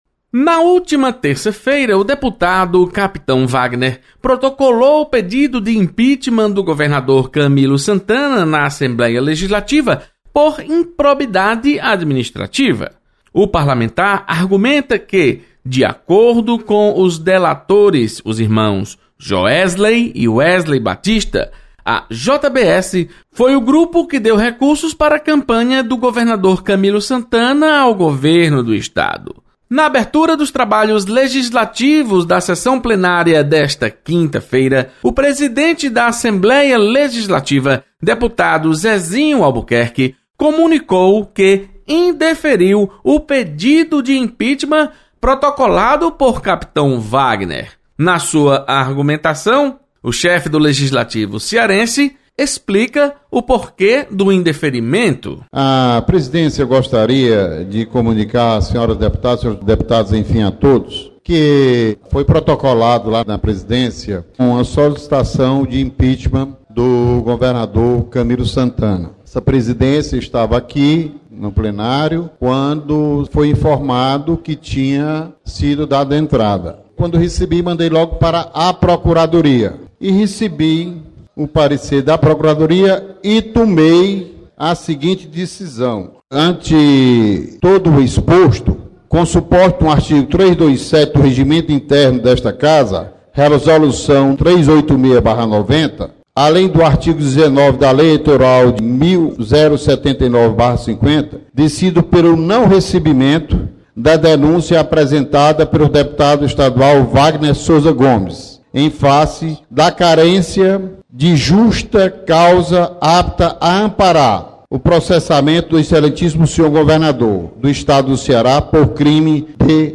Presidente da Assembleia Legislativa, deputado Zezinho Albuquerque, indefere pedido de impeachment de Camilo Santana. Repórter